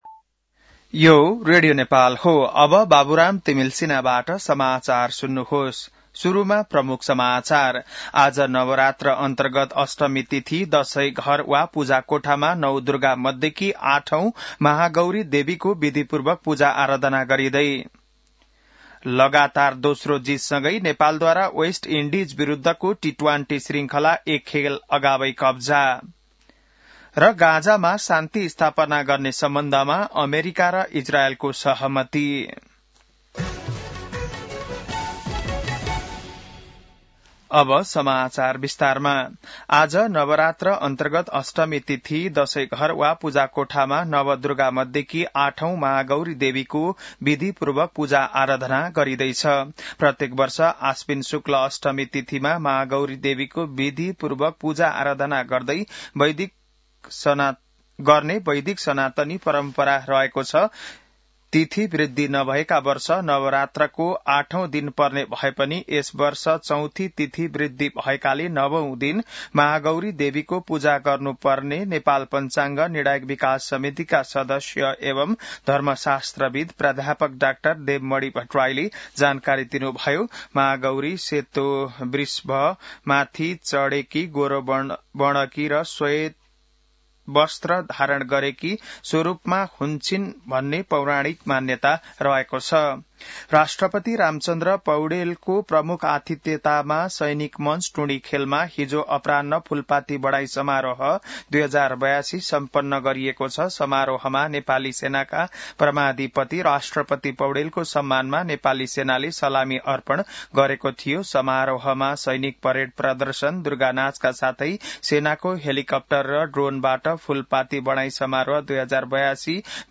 बिहान ९ बजेको नेपाली समाचार : १४ असोज , २०८२